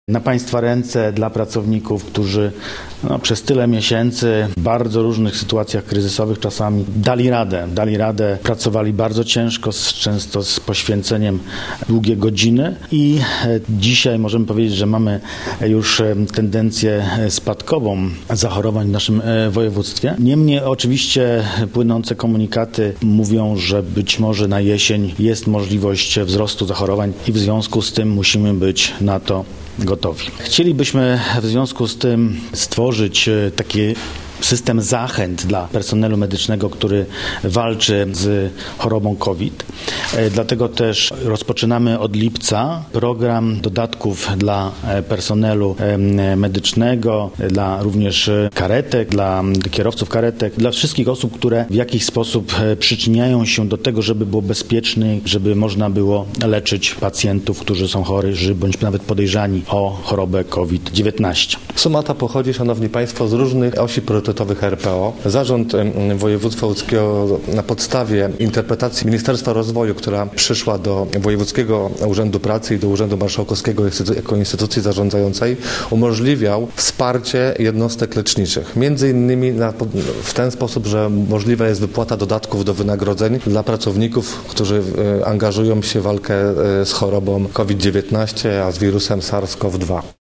– mówił Kamil Jeziorski, dyrektor Wojewódzkiego Urzędu Pracy.